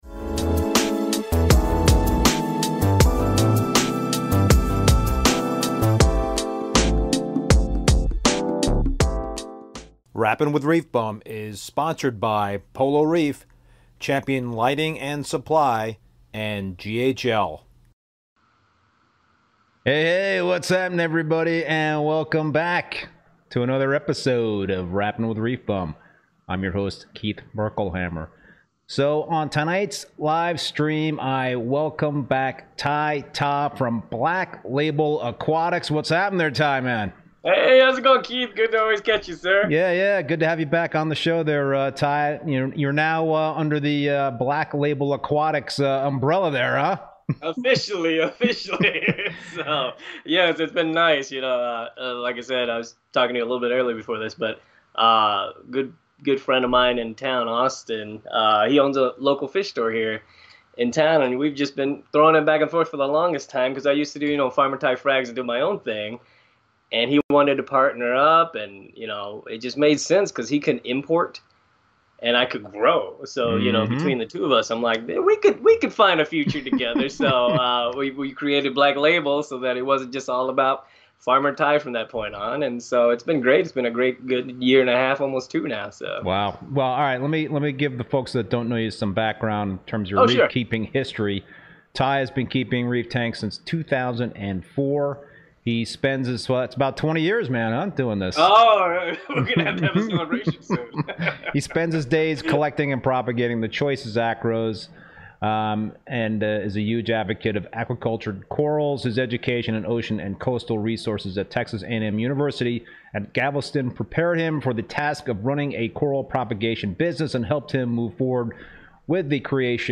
LIVE talk show